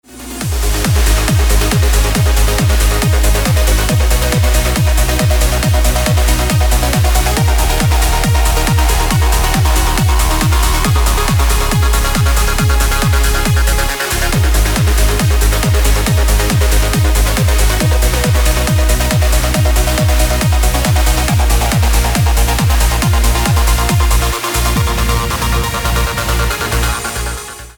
• Качество: 320, Stereo
громкие
dance
Electronic
EDM
club
Trance